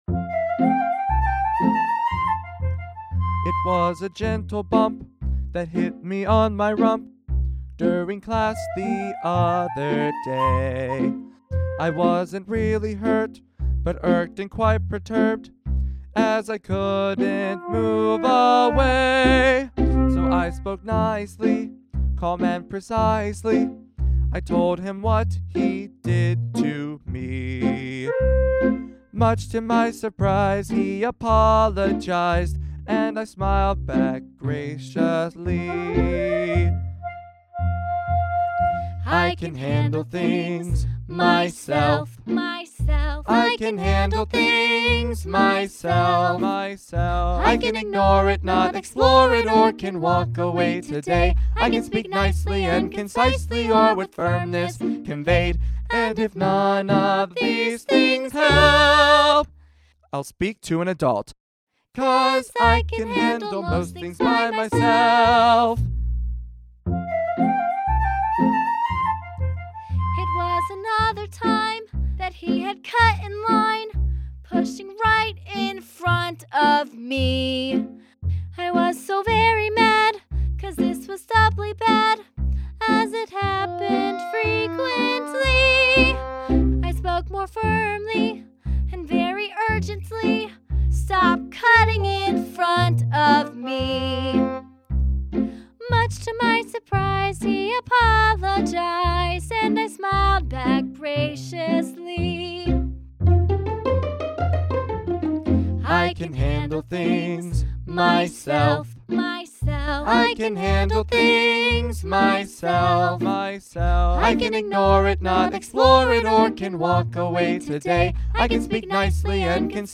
MP3 (with singing)